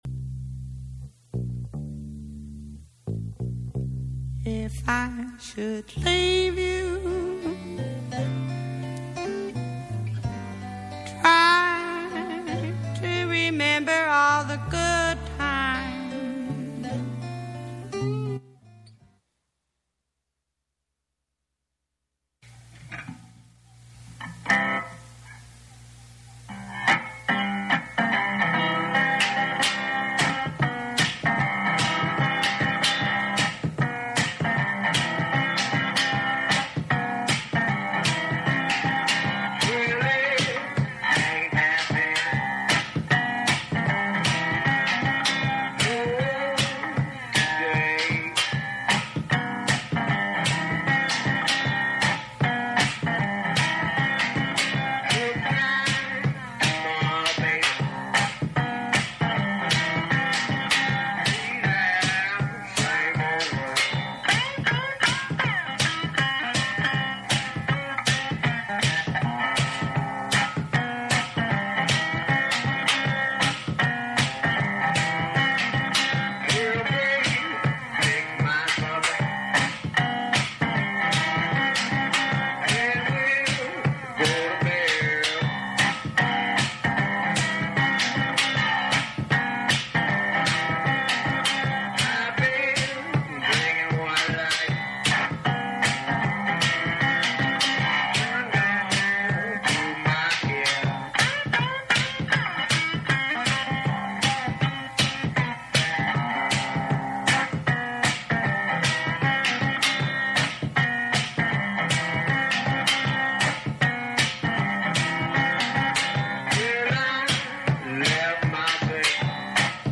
Recorded to CDR at the free103point9 Project Space and Gallery.